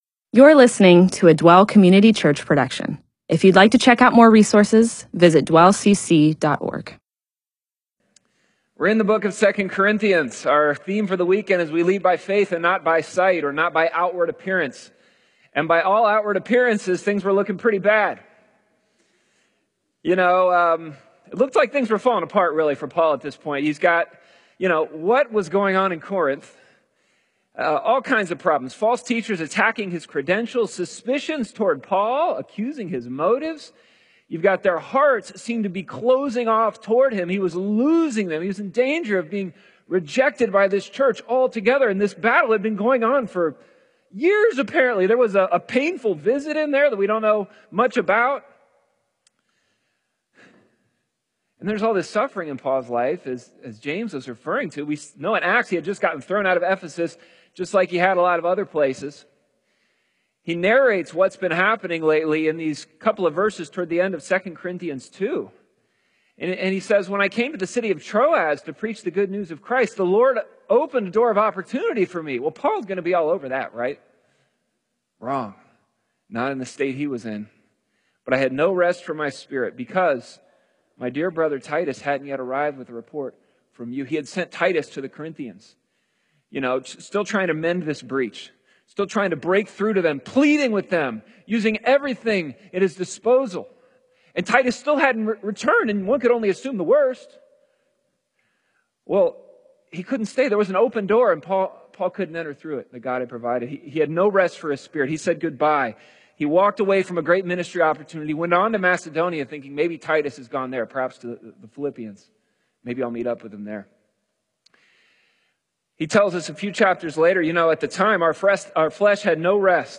MP4/M4A audio recording of a Bible teaching/sermon/presentation about 2 Corinthians 2:12-3:3.